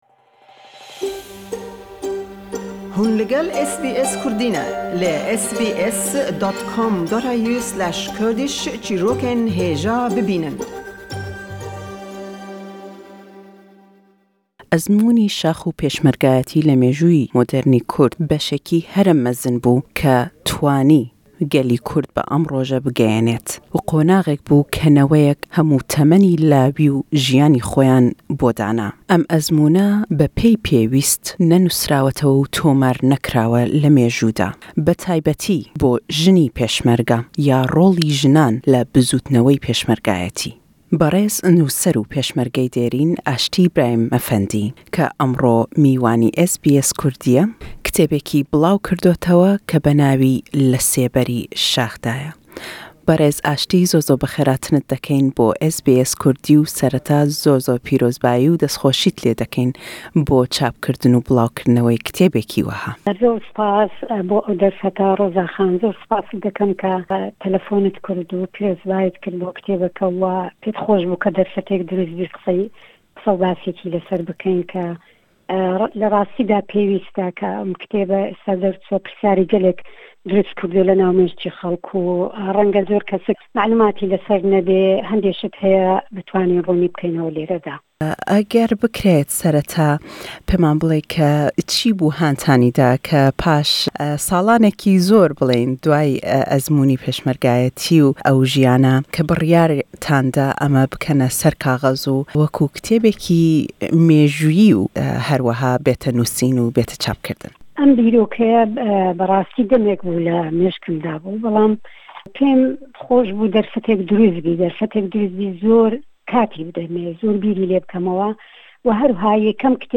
Le em lêdwane da pirsîyaryan lê dekeyn derbarey rollî jin le naw hêzî Pêşmerge da le ew serdeme, hokarî billaw kirdinwey em pertûke le êsta da.